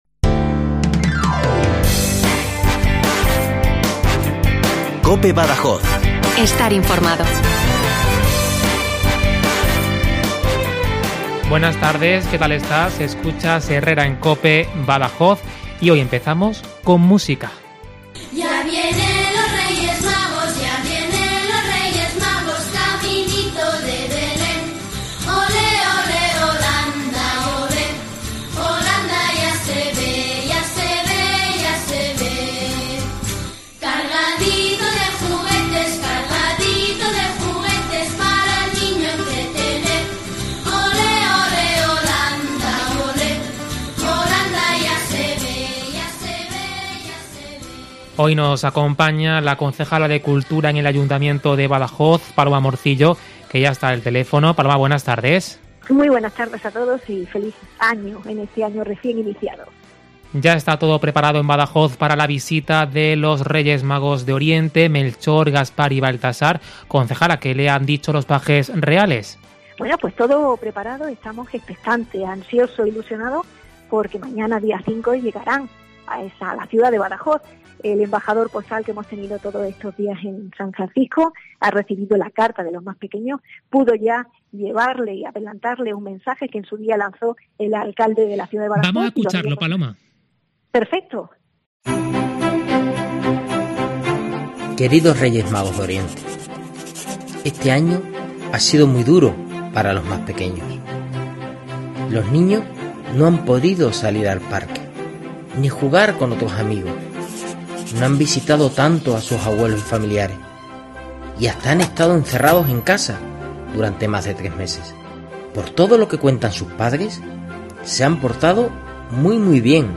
AUDIO: En 'Herrera en COPE' Badajoz hablamos con la concejala de Cultura, Paloma Morcillo, sobre la visita de los Reyes Magos a la ciudad.